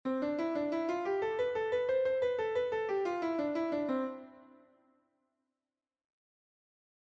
Exercices Gamme Majeure
Utiliser uniquement 3 doigts ( pouce, index, majeur ) pour chaque triolet :